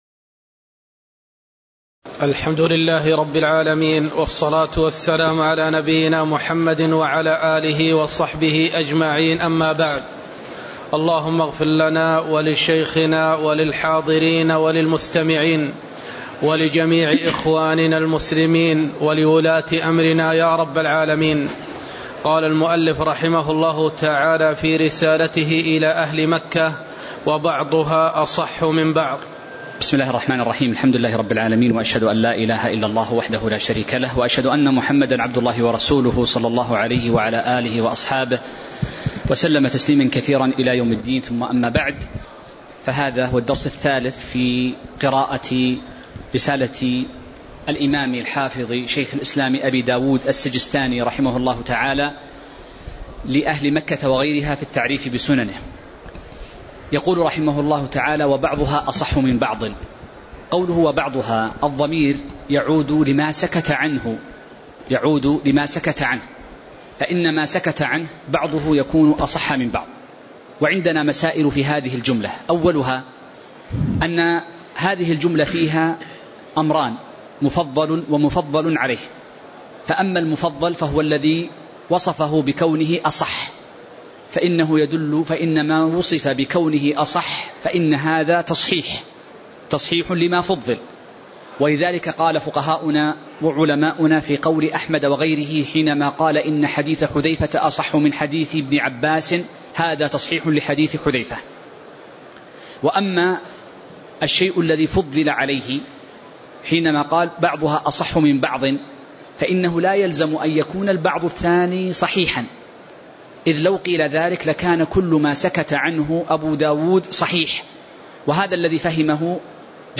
تاريخ النشر ١٤ شعبان ١٤٤٤ هـ المكان: المسجد النبوي الشيخ